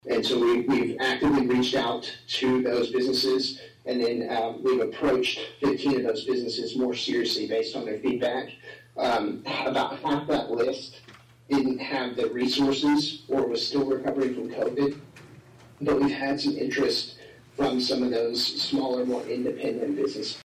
appeared via zoom at Wednesday’s Atlantic City Council meeting and provided an update on business recruitment.